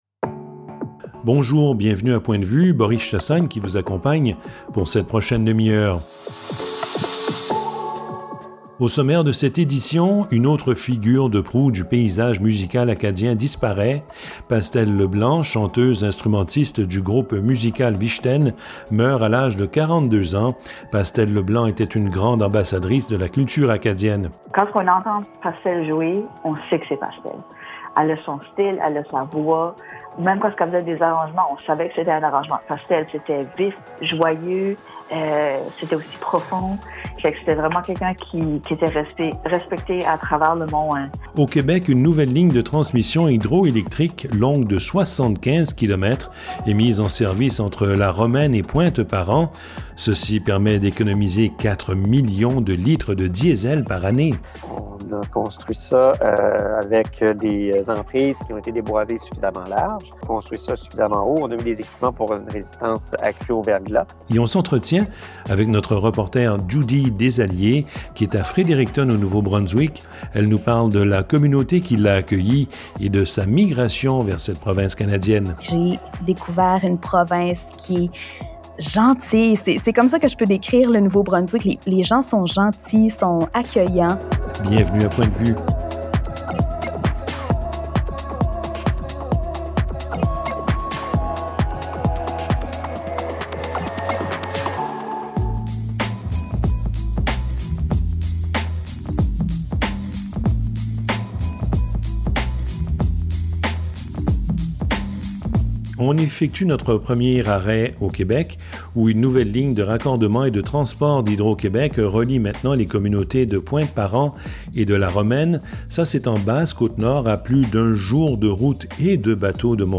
Points de vue, épisode 11 Points de vue, en ondes sur une quarantaine radios francophones canadiennes.